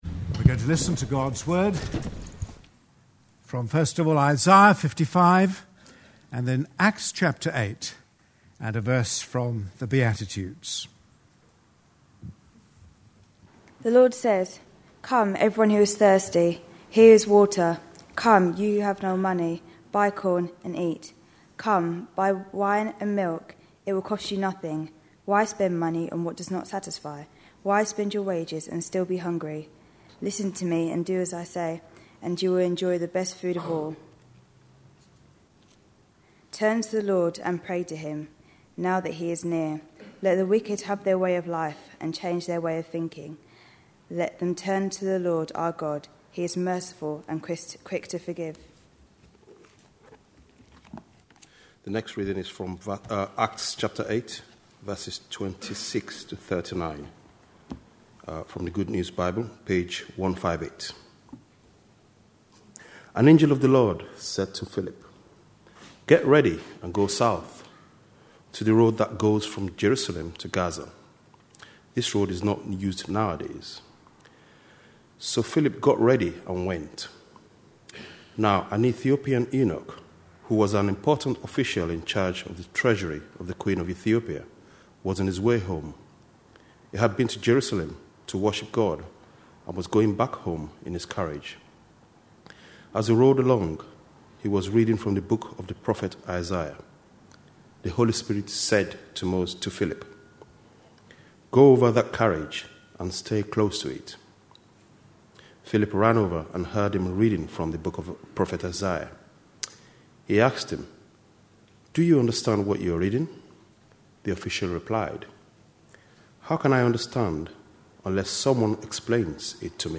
A sermon preached on 9th October, 2011, as part of our The Beatitudes. series.